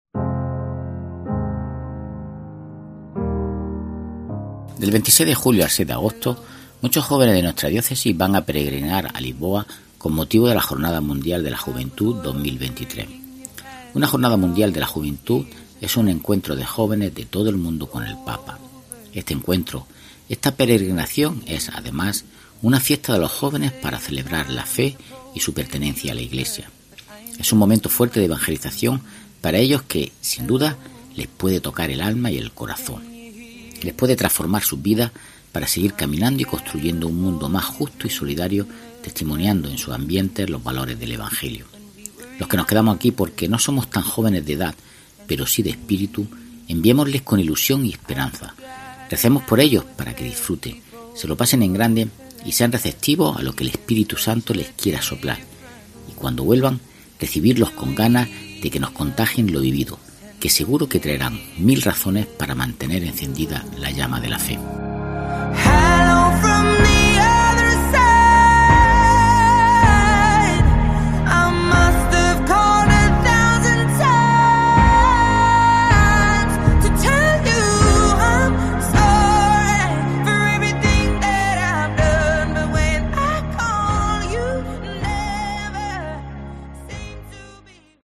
REFLEXIÓN